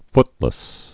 (ftlĭs)